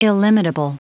Prononciation du mot illimitable en anglais (fichier audio)
Prononciation du mot : illimitable